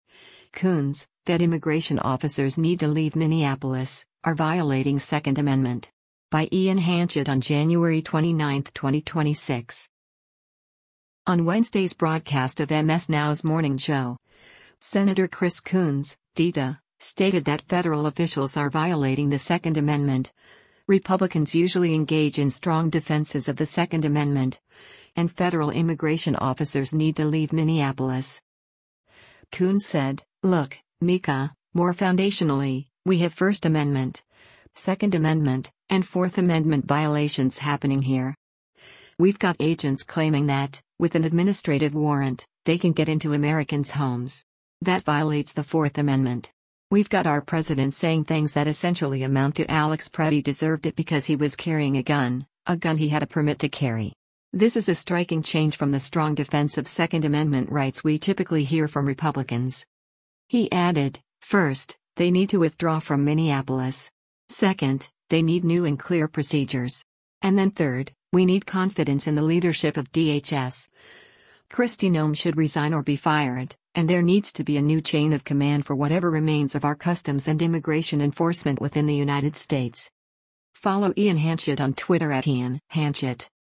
On Wednesday’s broadcast of MS NOW’s “Morning Joe,” Sen. Chris Coons (D-DE) stated that federal officials are violating the 2nd Amendment, Republicans usually engage in strong defenses of the 2nd Amendment, and federal immigration officers need to leave Minneapolis.